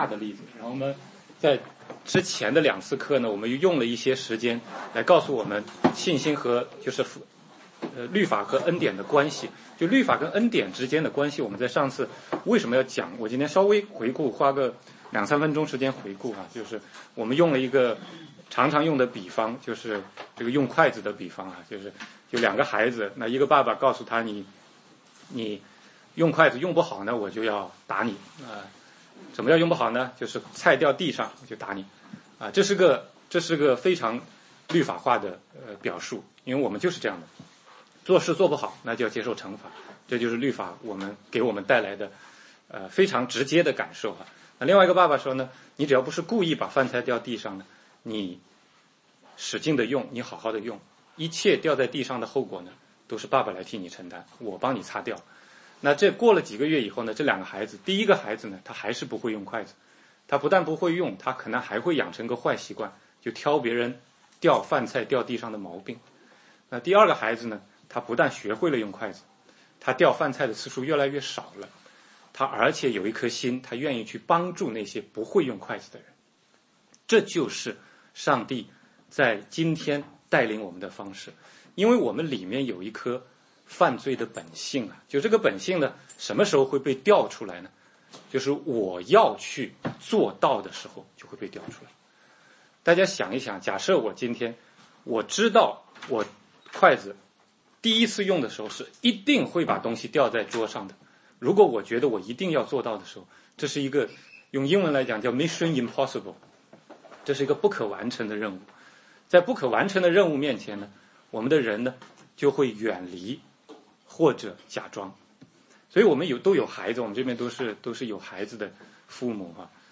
16街讲道录音 - 信心7 忧虑与小信